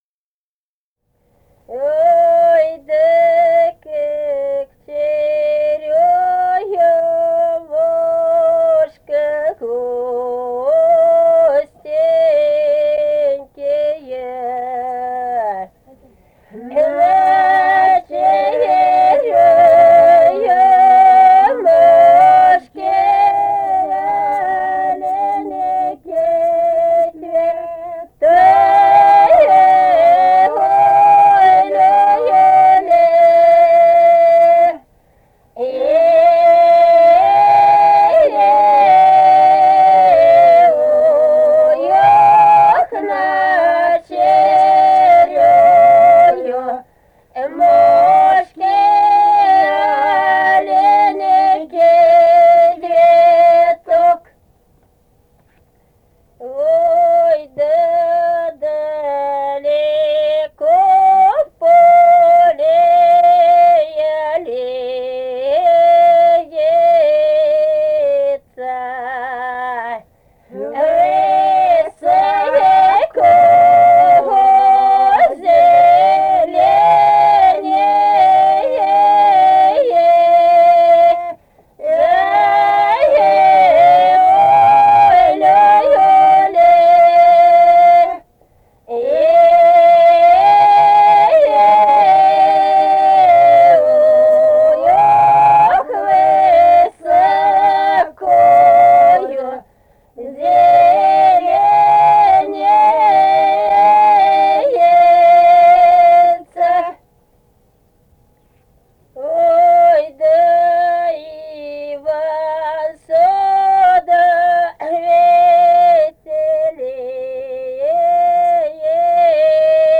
полевые материалы
Самарская область, с. Усманка Борского района, 1972 г. И1316-31